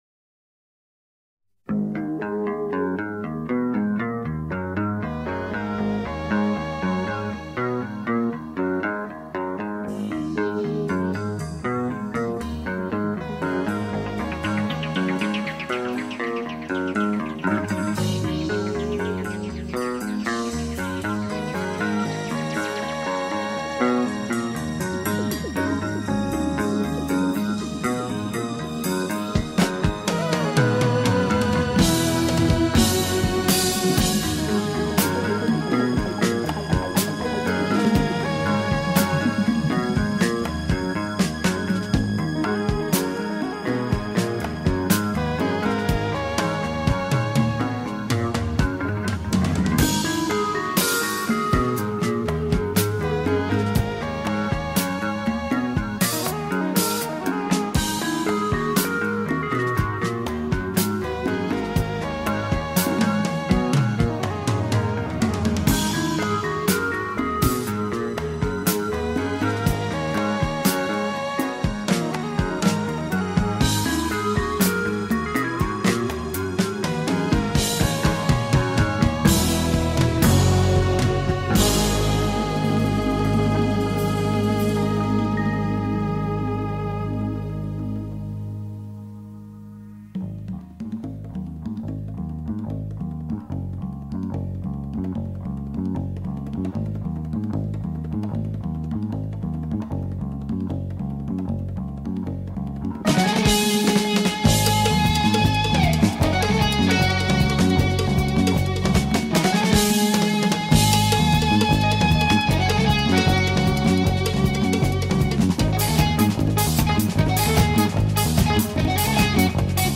Jazz Fusion, Jazz Rock, Flamenco